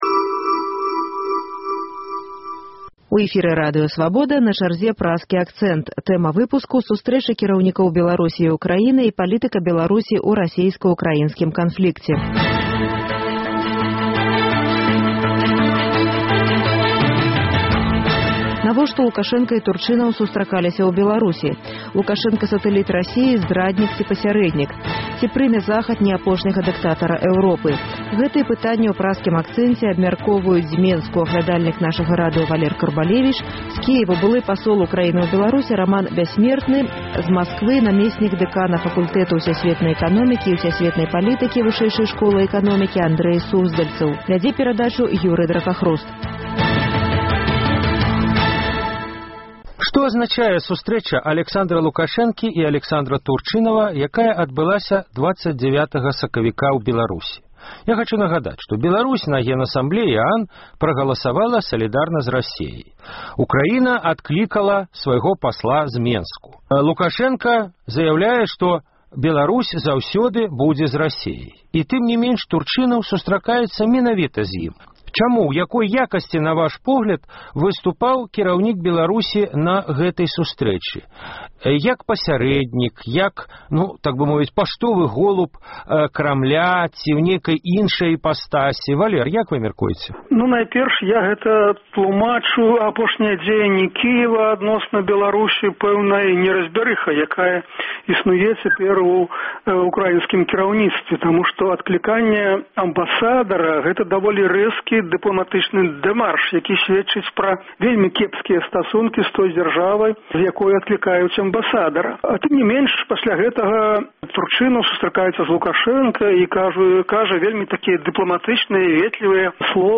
Лукашэнка – сатэліт Расеі, здраднік ці пасярэднік? Ці прыме Захад «неапошняга дыктатара» Эўропы? Гэтыя пытаньні абмяркоўваюць аглядальнік нашага радыё